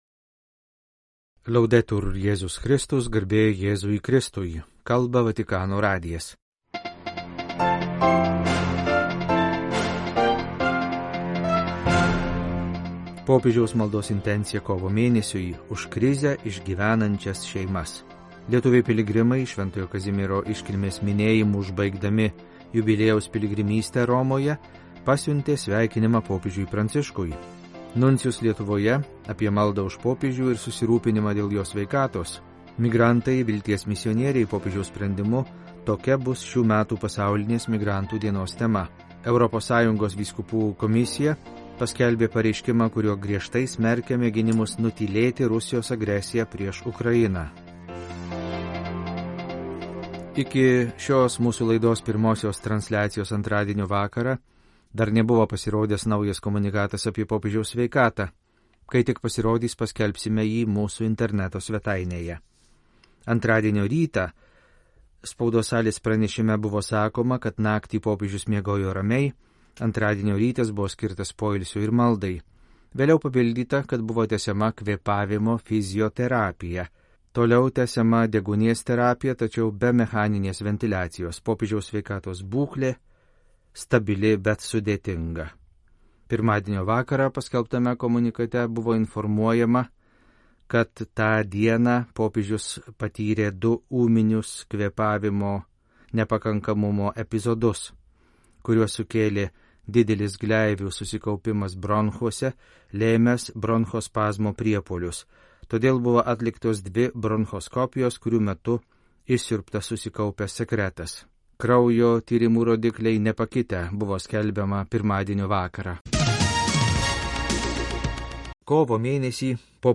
Žinių laida apie Popiežiaus veiklą ir Bažnyčios gyvenimą - Podcast - Radio Vaticana - Vatican News